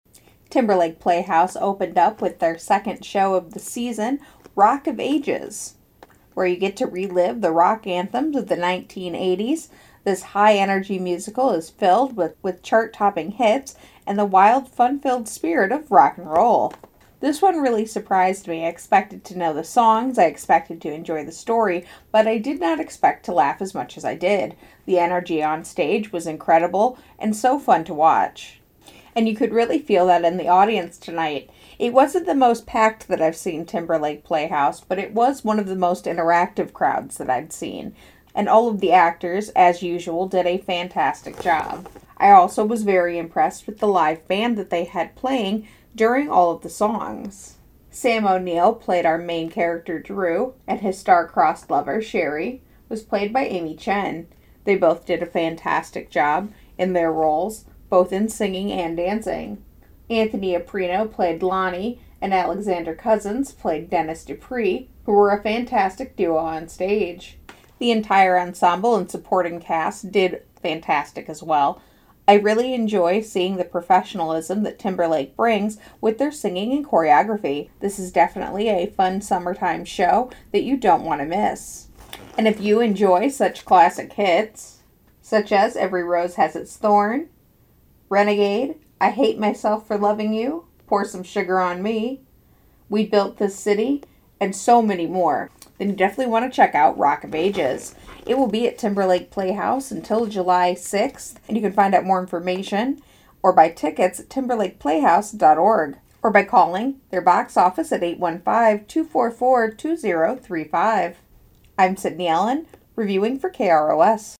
TLP-Review-Rock-of-Ages.mp3